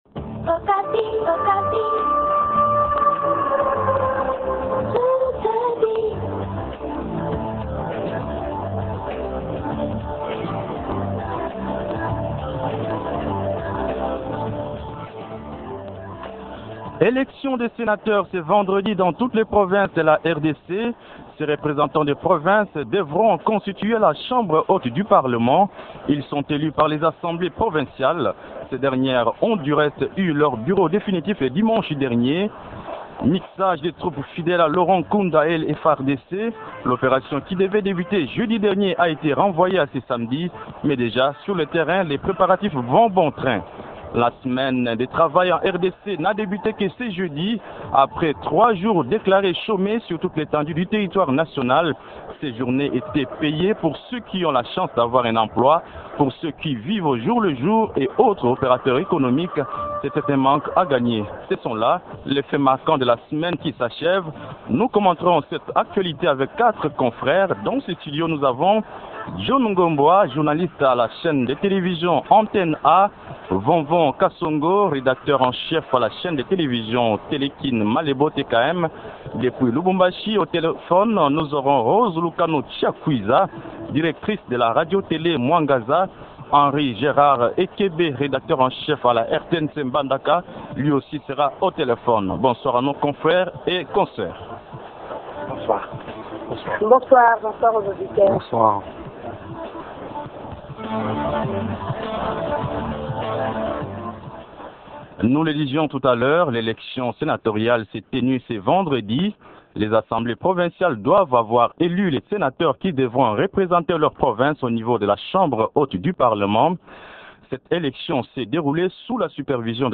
Télécharger Trois thèmes à débattre :